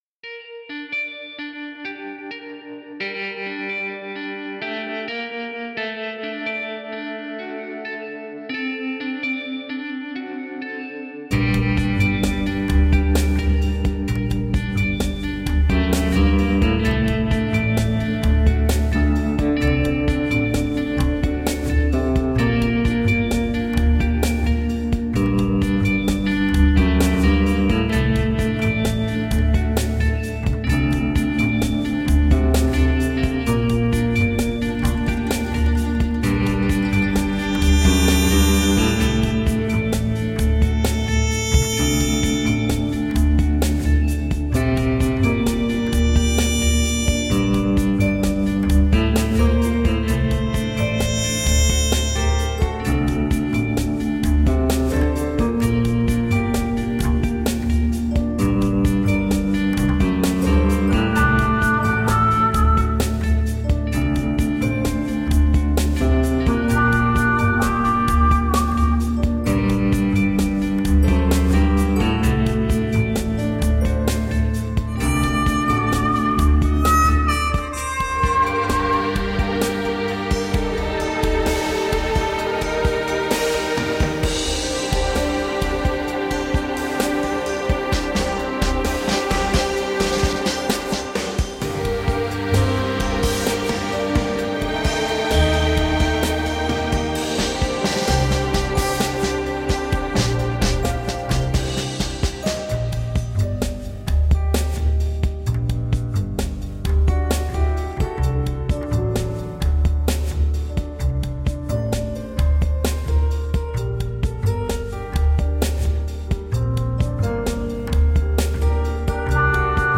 Groove laden chillout funk.
Tagged as: Jazz, Funk